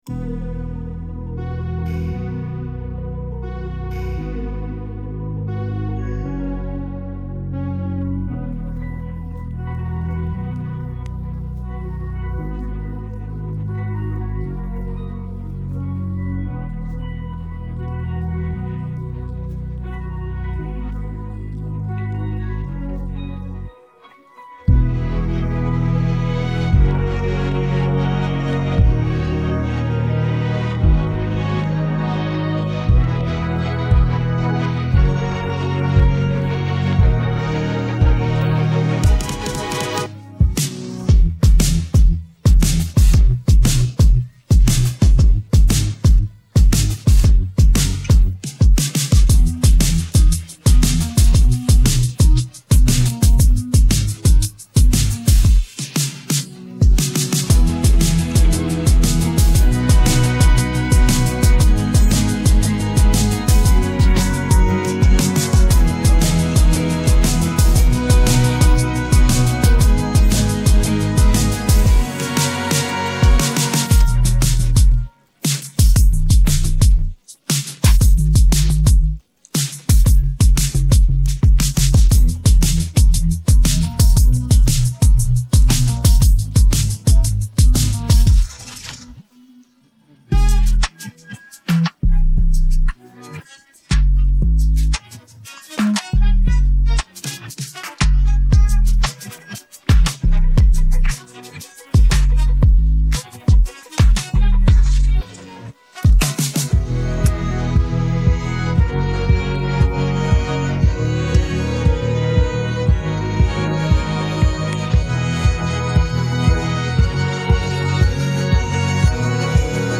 पृष्ठ ध्वनि